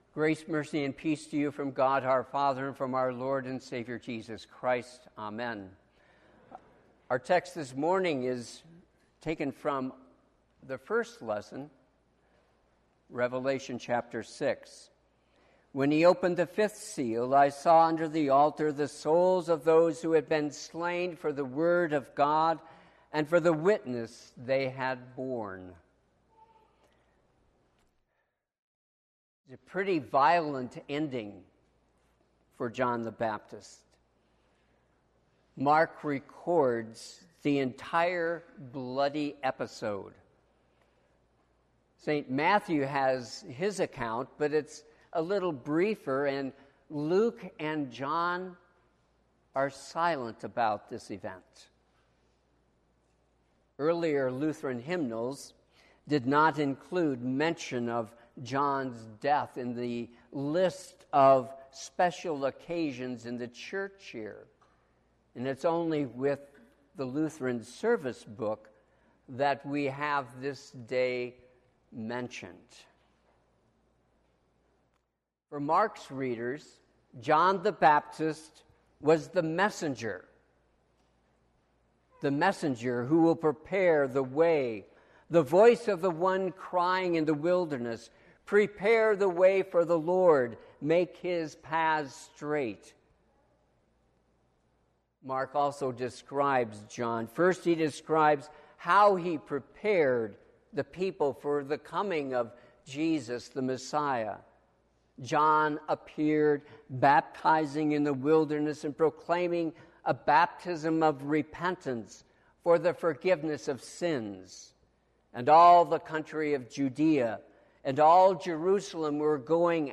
Sermon - 8/29/2021 - Wheat Ridge Lutheran Church, Wheat Ridge, Colorado